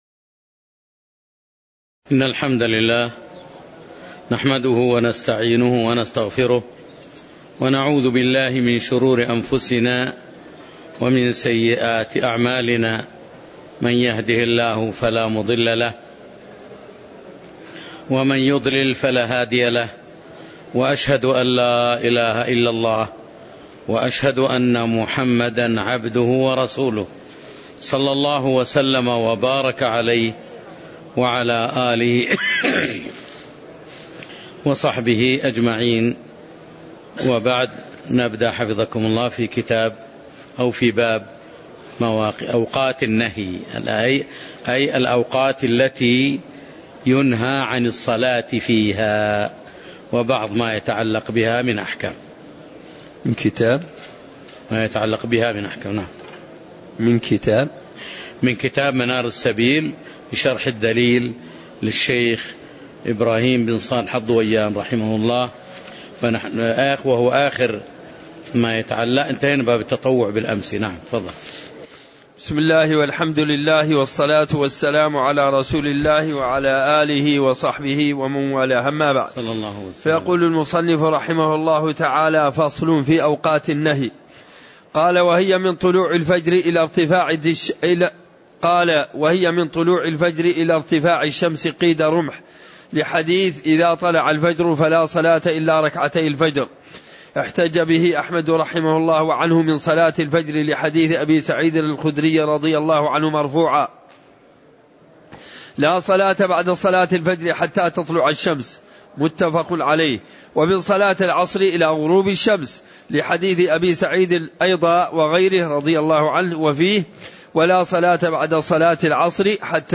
تاريخ النشر ٢٤ محرم ١٤٣٩ هـ المكان: المسجد النبوي الشيخ